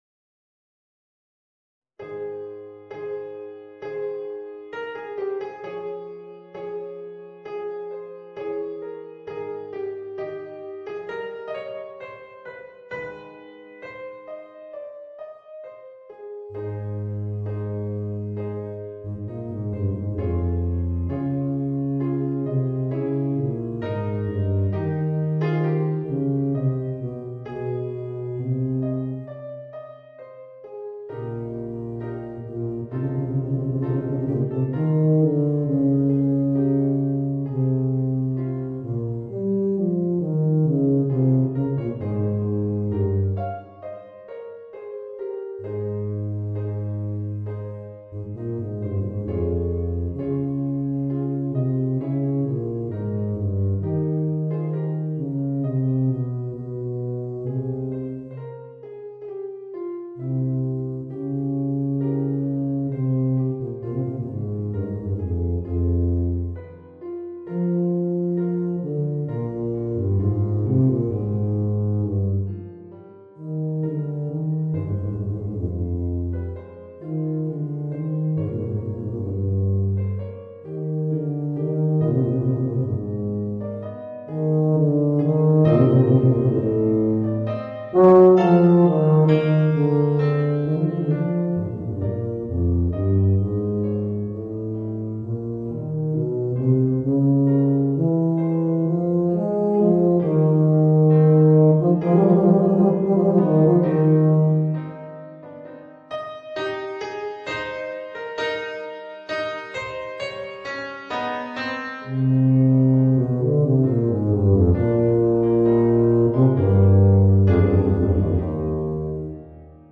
Voicing: Bb Bass and Organ